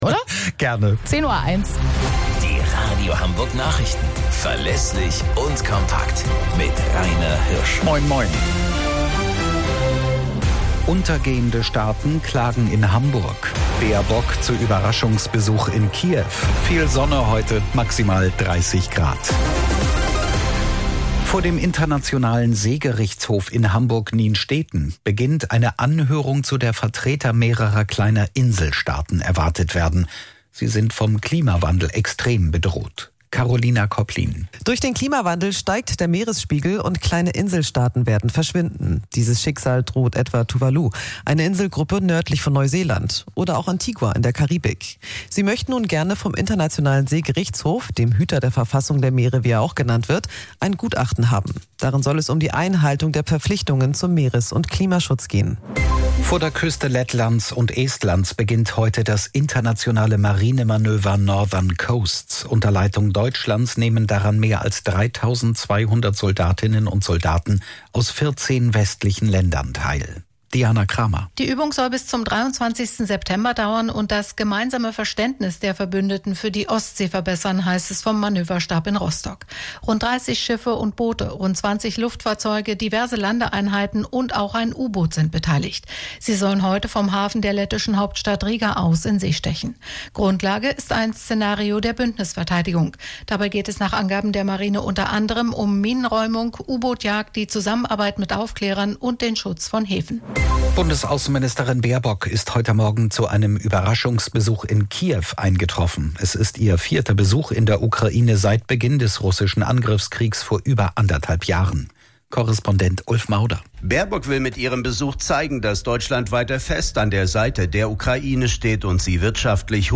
Radio Hamburg Nachrichten vom 11.09.2023 um 17 Uhr - 11.09.2023